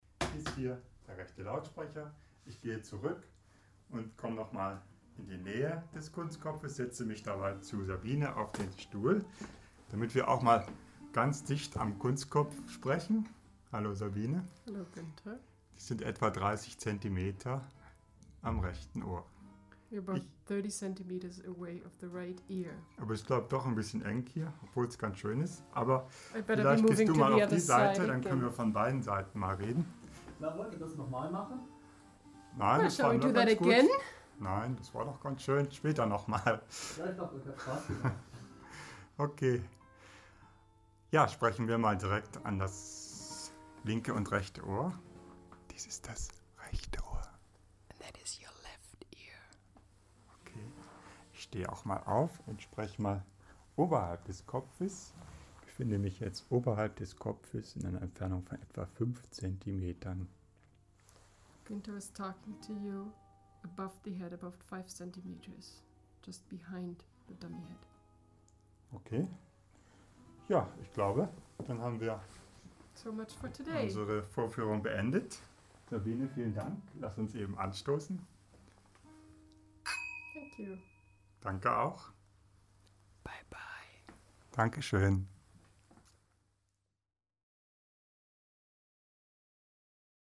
Binaural rhythms to listen to and download online